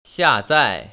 [xiàzài] 시아자이  ▶